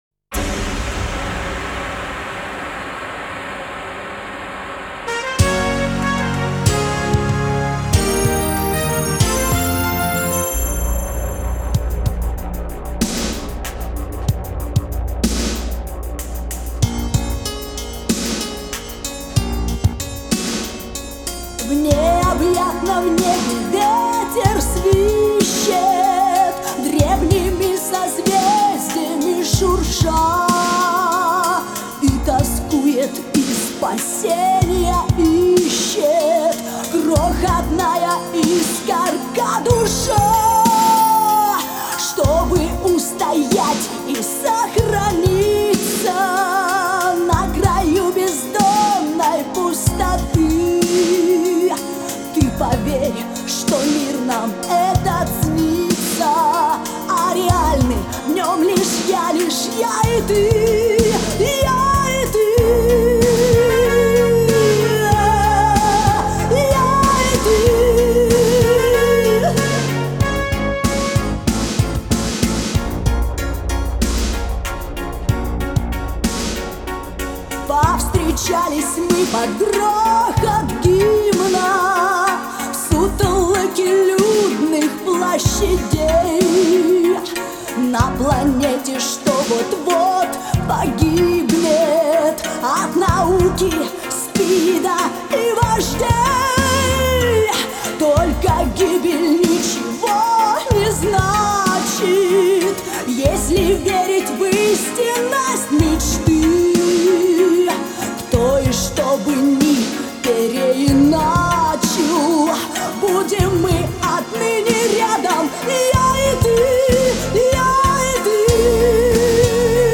Жанр: Russian Pop, Pop
Стиль: Russian Pop